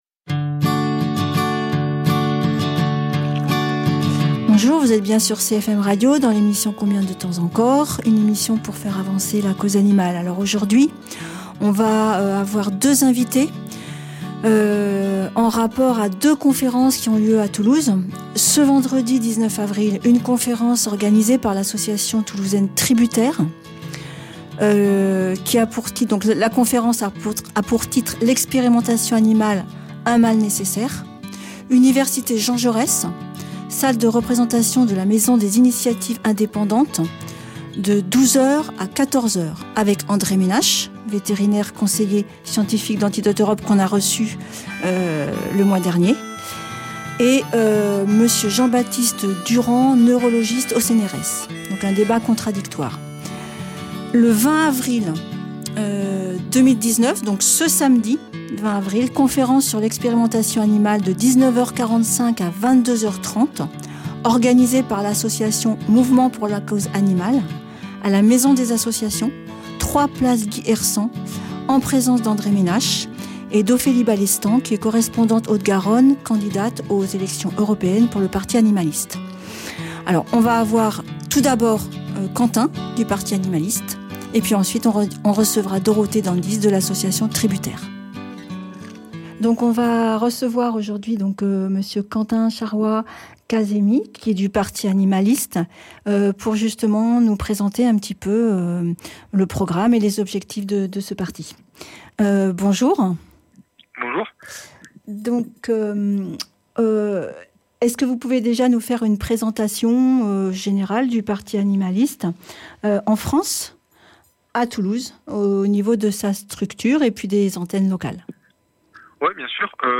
Émissions